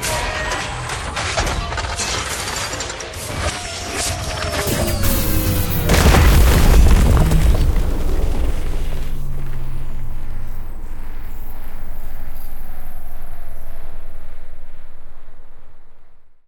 land.wav